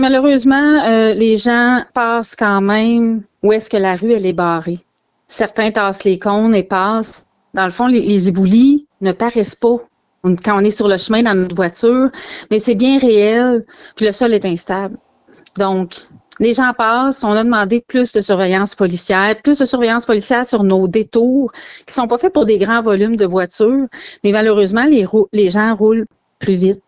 La mairesse a expliqué que des blocs de béton devraient être installés vendredi ou lundi prochain pour empêcher les voitures d’y passer.